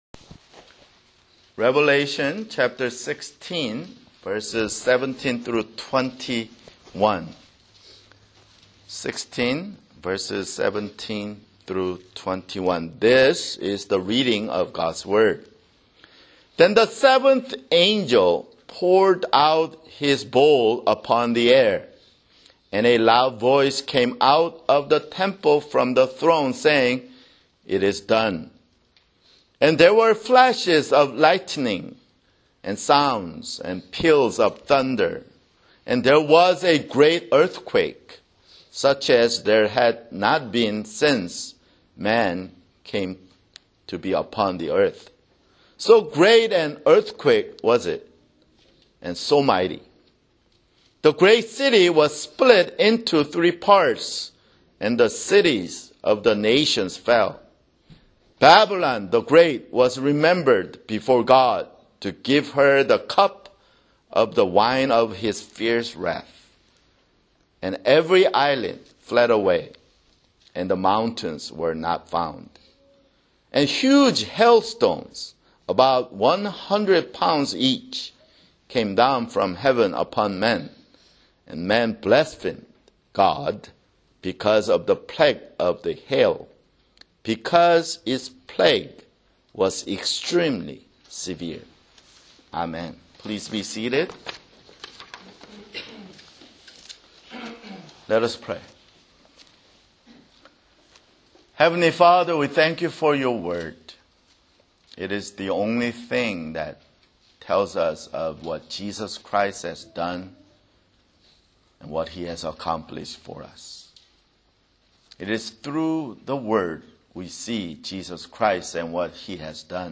[Sermon] Revelation (65)